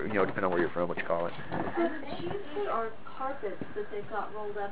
Smoking Room Child's voice
You can hear a child’s voice over the top of our investigator.
Girls-voice-in-smoking-room.wav